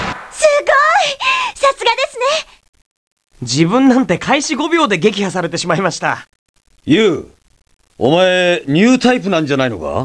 しかし、クリアできないときは得点によってモーリンのコメントが変わる。また、クリアしたときは被弾率によってコメントが変わる。